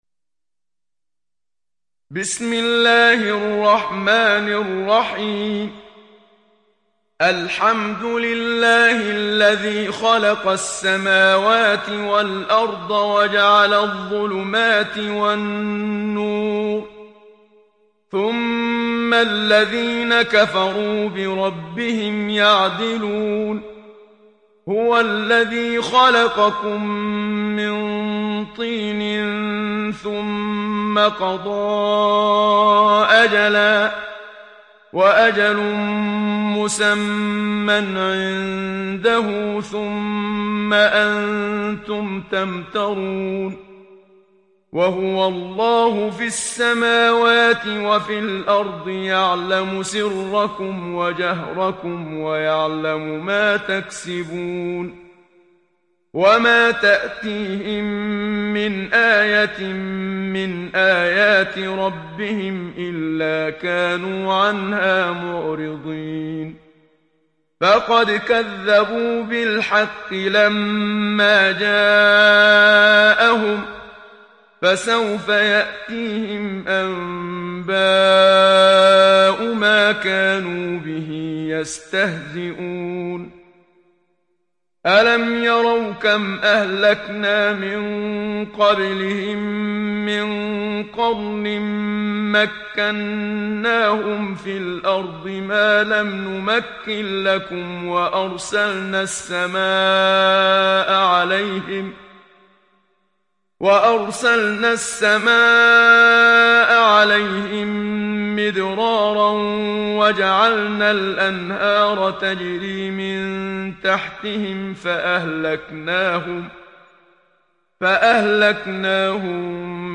Enam Suresi mp3 İndir Muhammad Siddiq Minshawi (Riwayat Hafs)